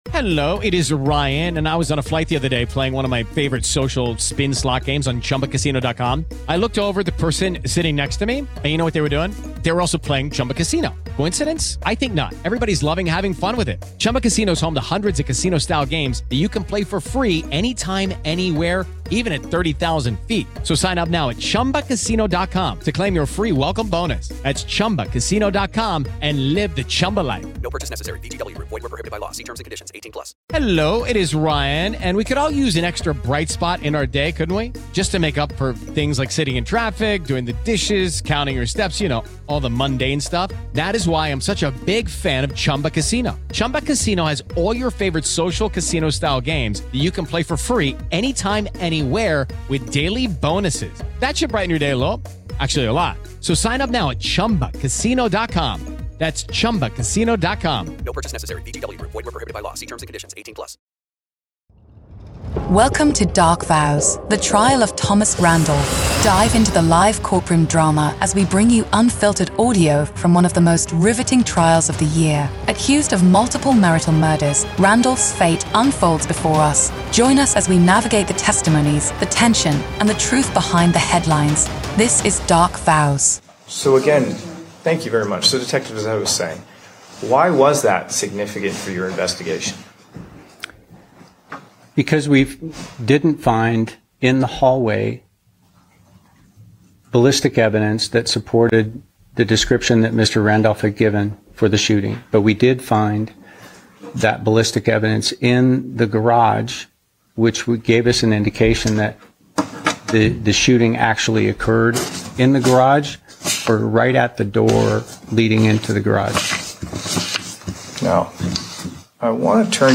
Every episode beams you directly into the heart of the courtroom, with raw, unedited audio from testimonies, cross-examinations, and the ripple of murmurs from the gallery.
We accompany the live audio with expert legal insights, breaking down the day's events, the strategies in play, and the potential implications of each revelation.